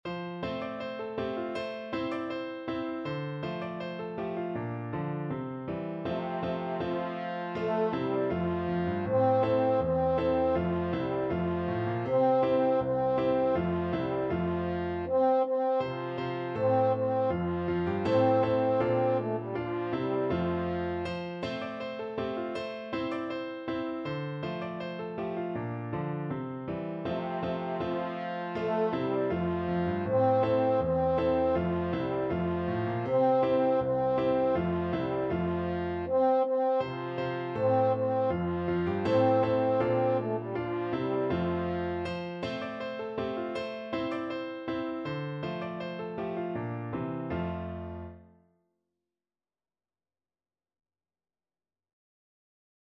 French Horn version
2/4 (View more 2/4 Music)
Steadily =c.80
world (View more world French Horn Music)